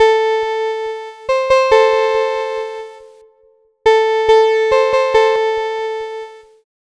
Cheese Lix Synth 140-A.wav